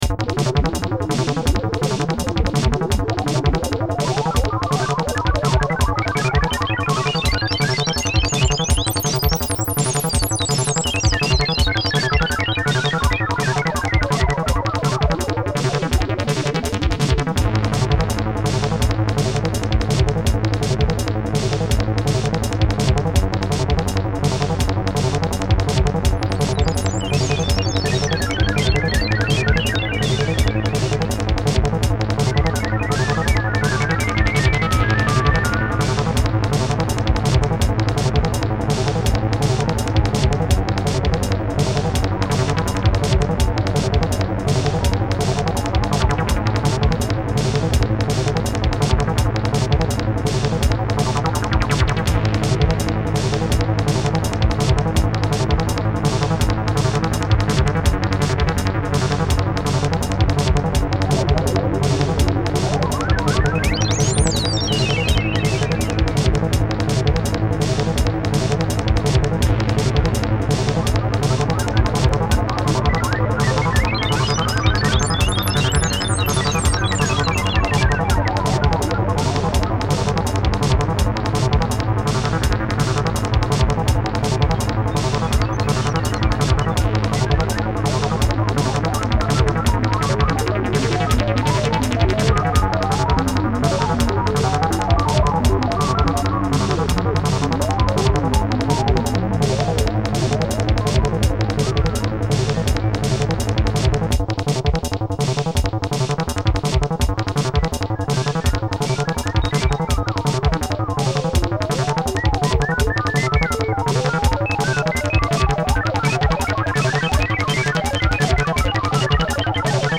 Ce que je préfère c'est la ligne de basse !
Je l'imagine bien comme bande son d'une scène de poursuite dans un film de SF...